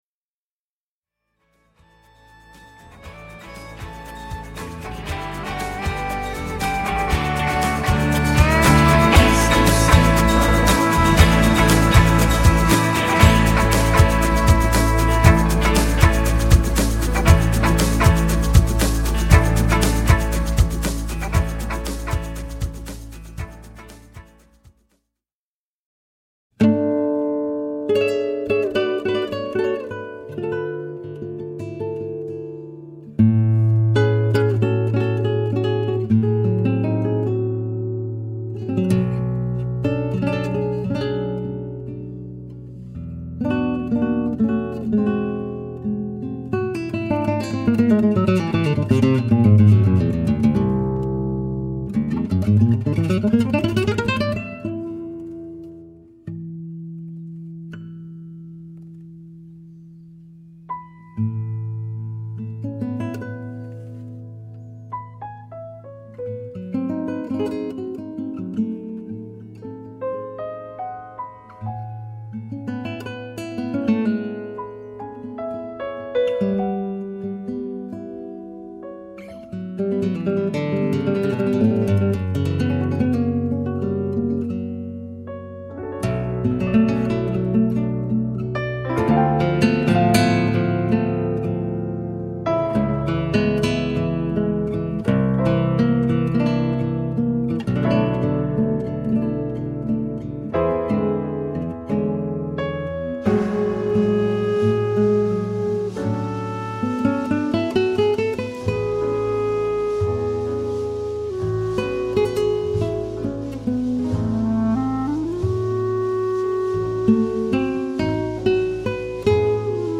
É um repertório pianístico e percussivo